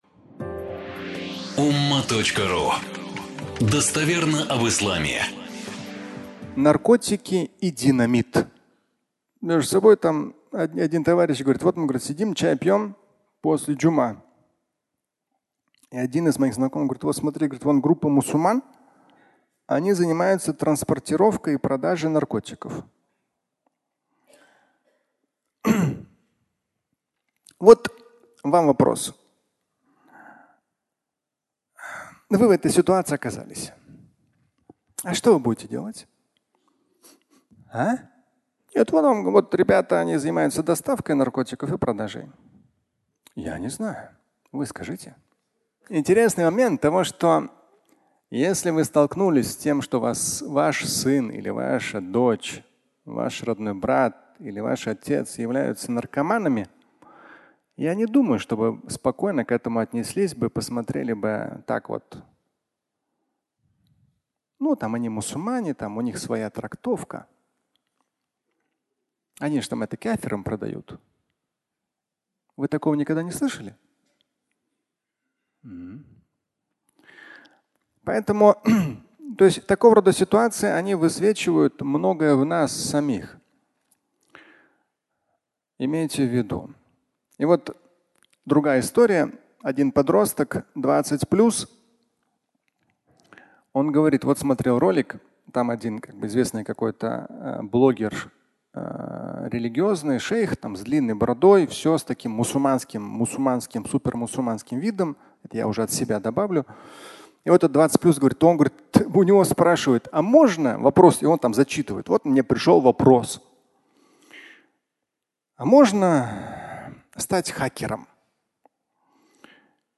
Наркотики и динамит (аудиолекция)